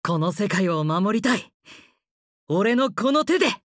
第四则配音片段：
就像辛无时无刻对伙伴的关爱与保护一样，邻家大哥哥般的温柔声线一响起，无形中就给人以安全感与信任感。